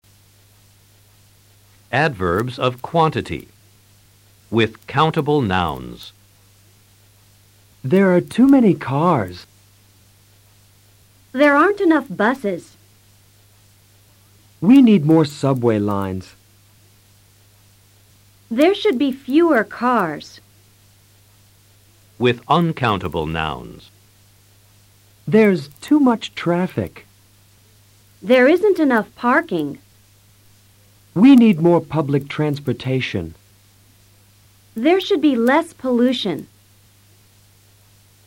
Escucha a los profesores y presta atención al uso de los ADVERBIOS DE CANTIDAD con sustantivos contables y no contables.